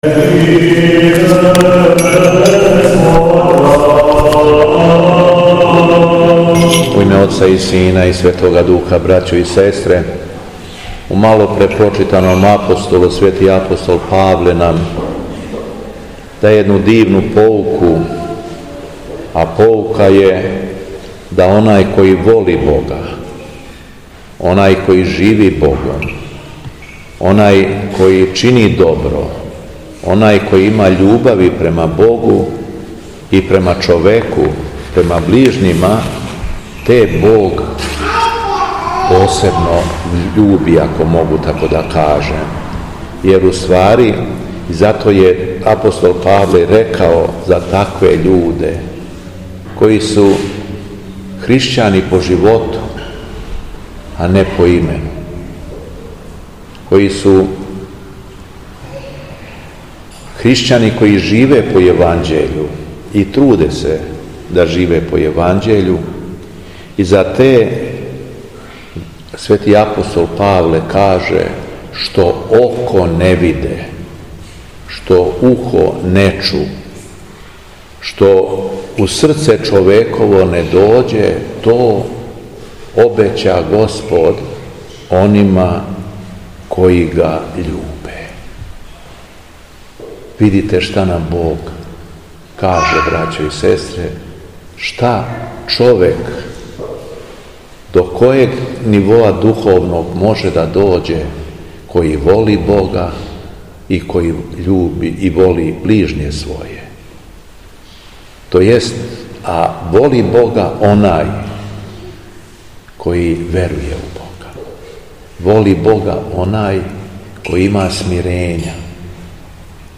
Беседа Његовог Високопреосвештенства Митрополита шумадијског г. Јована
Митрополит Јован се након прочитаног Јеванђеља по Матеју обратио сабраном народу: